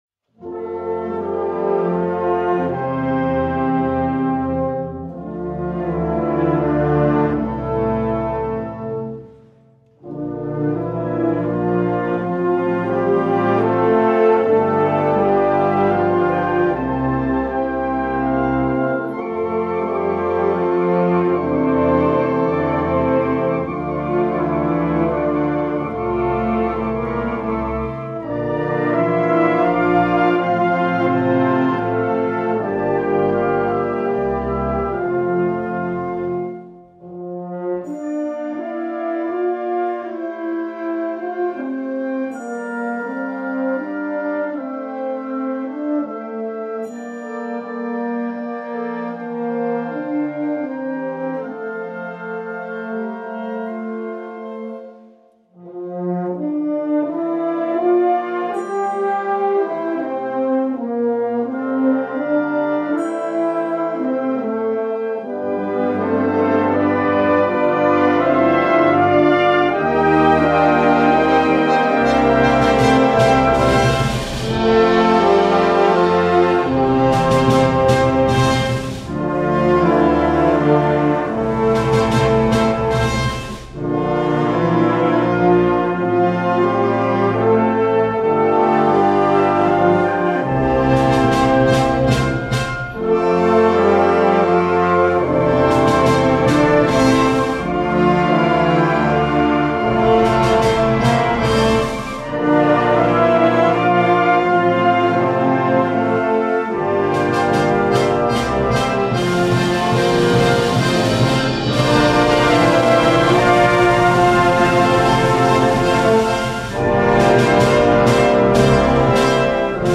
Gattung: Messe für Blasorchester
Besetzung: Blasorchester